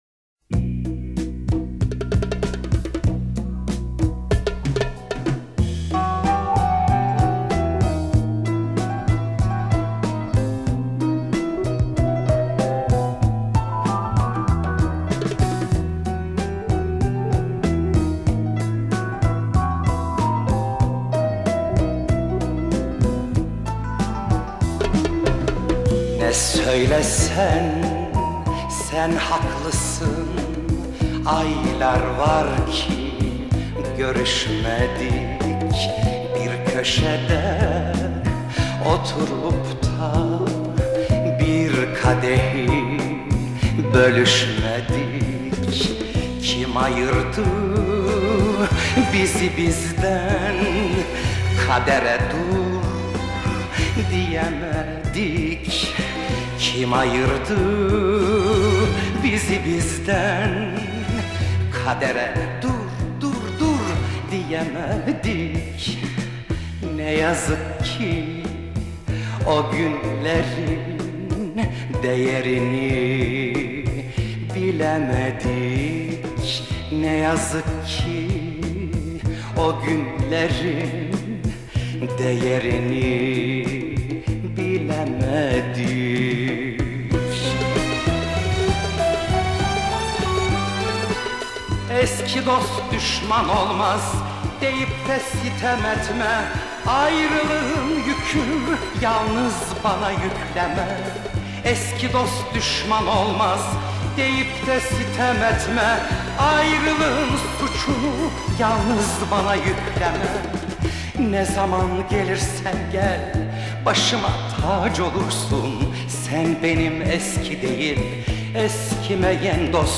ترانه ترکی استانبولی türkçe şarkı